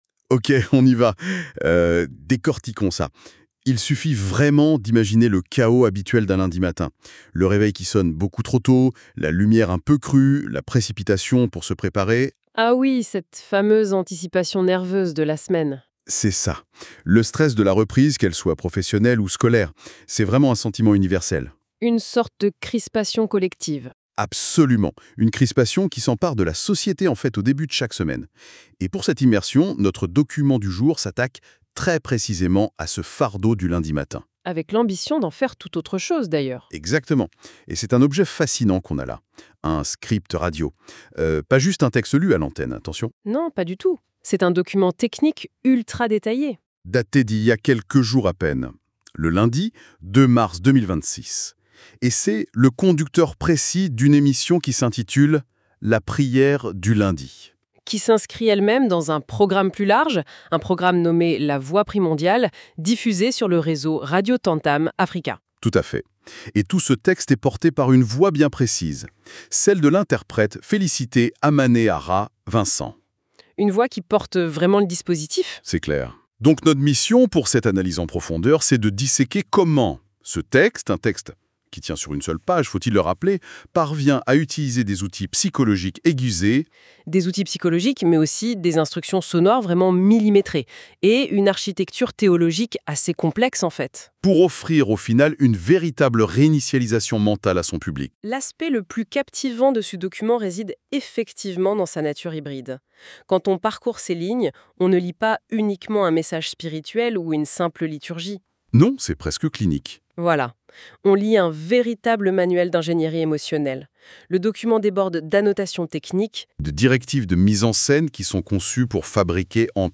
Solennelle · Apaisante · Spirituelle